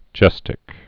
(jĕstĭk)